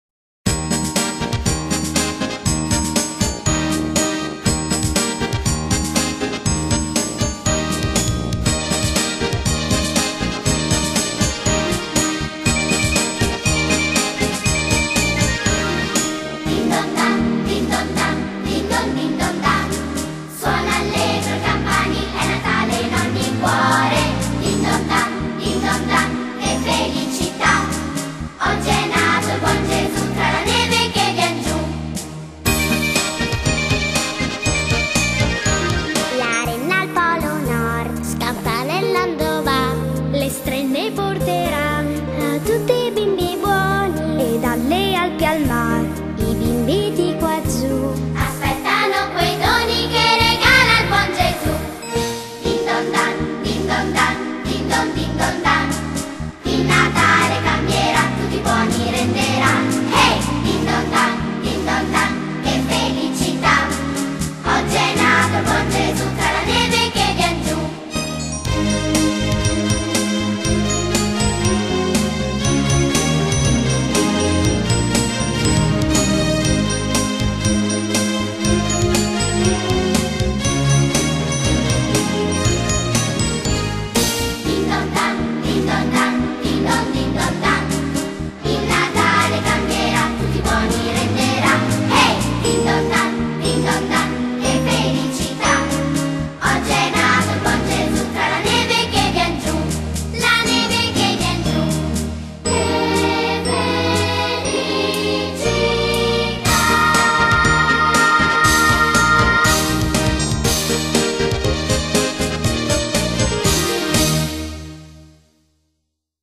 coro dei genitori- Dicembre 2018